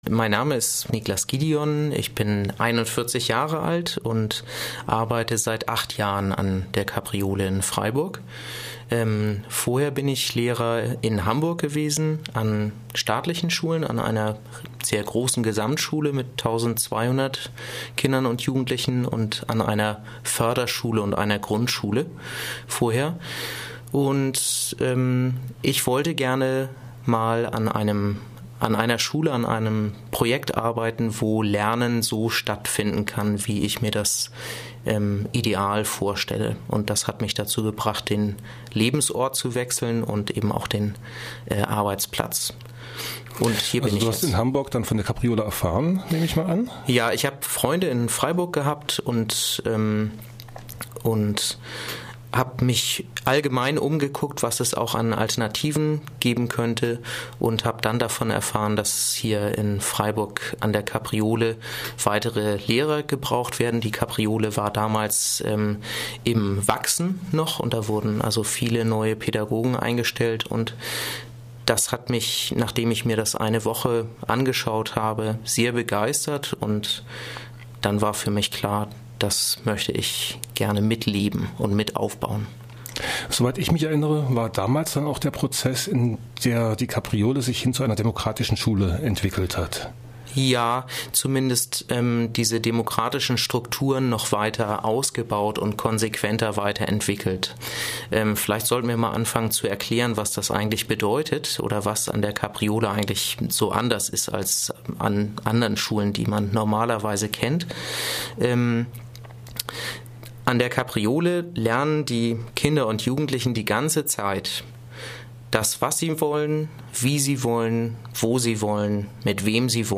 Lehrer an der Freien Demokratischen Schule Kapriole war zu Gast in Punkt12, dem Mittagsmagazin von Radio Dreyeckland.